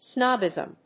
音節snób・bism 発音記号・読み方
/‐bɪzm(米国英語)/